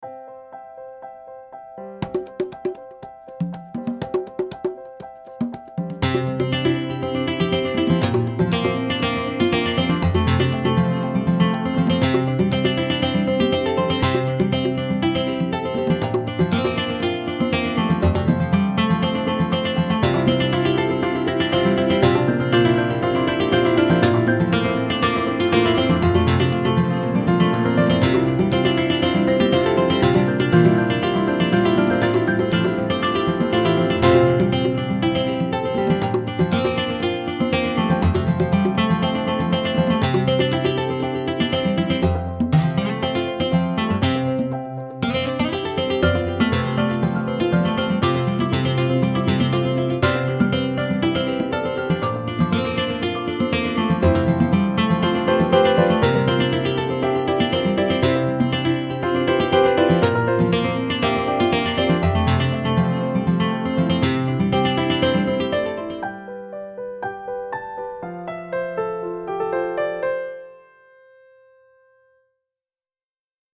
This is totally how Garageband makes you feel, even if you only put some loops together.
My first attempt makes me feel like a folk rock god.